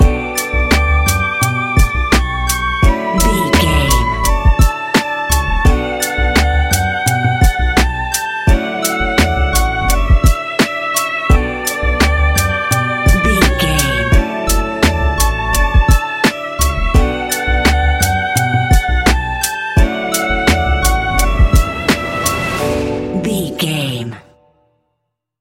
Ionian/Major
D♭
laid back
Lounge
sparse
new age
chilled electronica
ambient
atmospheric